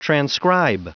Prononciation du mot transcribe en anglais (fichier audio)
Prononciation du mot : transcribe